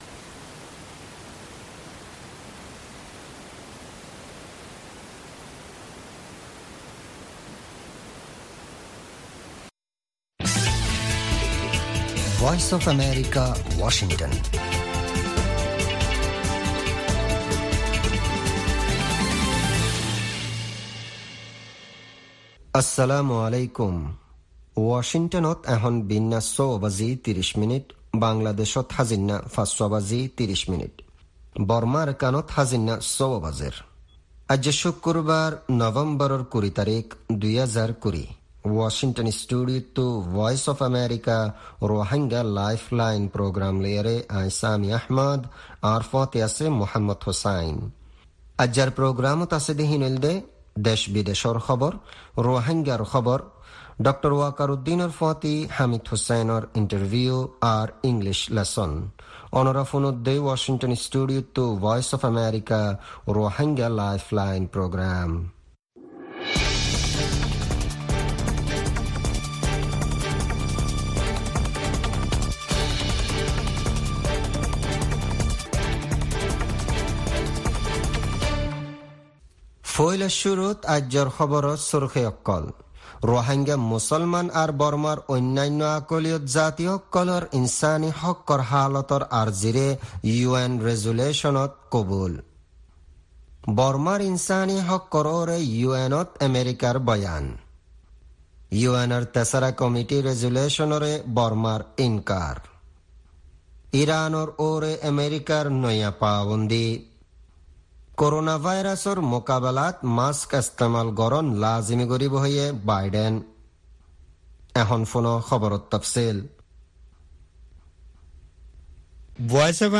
Rohingya Broadcast
News Headlines